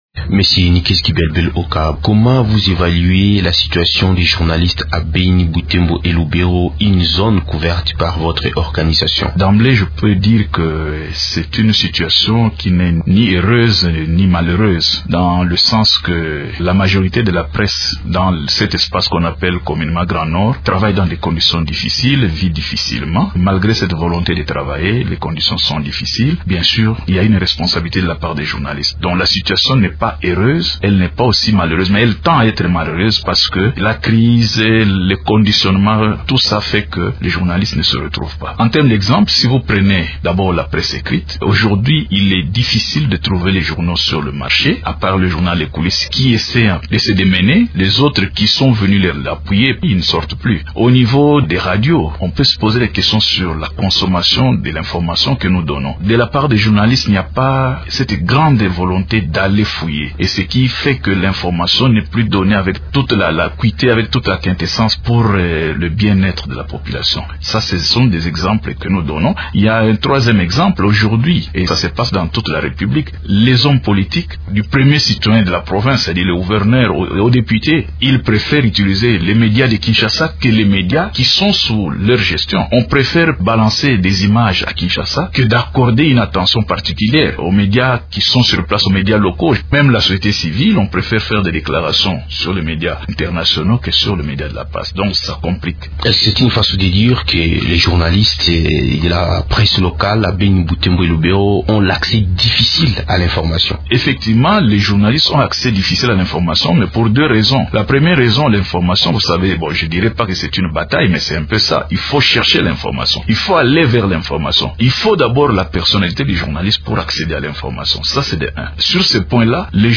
interrogé